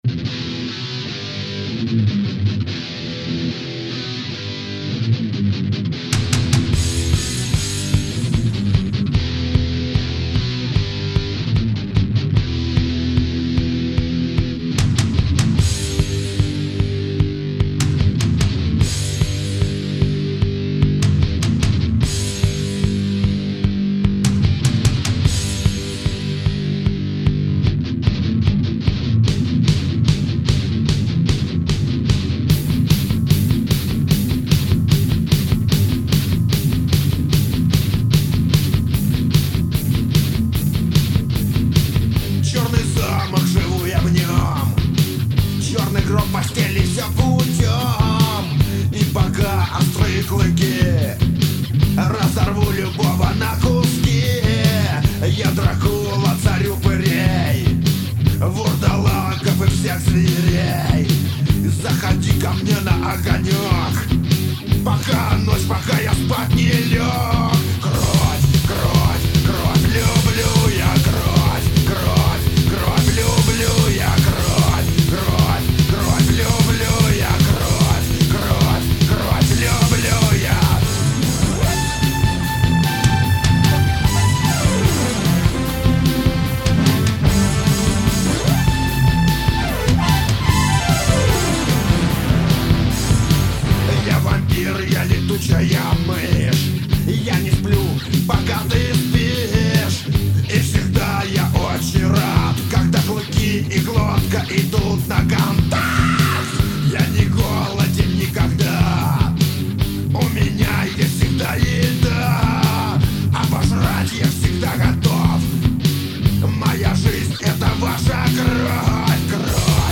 Жанр: Punk Rock